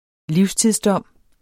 Udtale [ ˈliwstiðs- ]